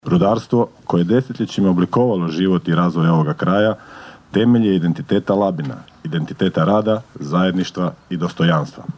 Komemoracija na Krvovoj placi na Vinežu
"Labinska republika ostala je upamćena kao prvi otpor fašizmu, kroz hrabrost naših rudara", kazao je tom prilikom zamjenik gradonačelnika Labina Goran Vlačić: (